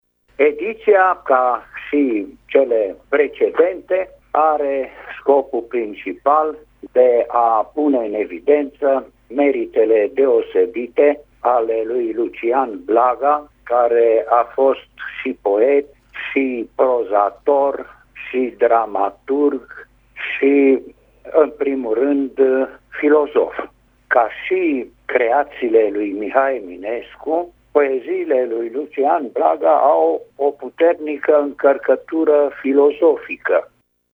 Radio România Tg. Mureș găzduiește vineri, 28 iulie, de la ora 12, o sesiune de comunicări științifice intitulată „Lucian Blaga și opera sa în secolul XXI”.
Unul dintre moderatorii evenimentului, academicianul Alexandru Surdu, vicepreședintele Academiei Române, a ținut să sublinieze actualitatea filosofiei lui Lucian Blaga: